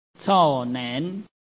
臺灣客語拼音學習網-客語聽讀拼-海陸腔-鼻尾韻
拼音查詢：【海陸腔】nen ~請點選不同聲調拼音聽聽看!(例字漢字部分屬參考性質)